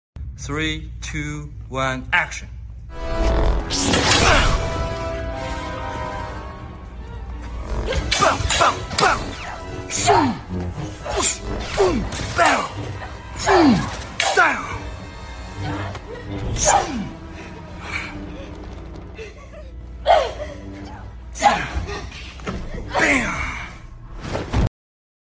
Hayden Christensen and Ariana Greenblatt practicing their choreography for their Anakin VS Ahsoka duel from the Ahsoka series season 1!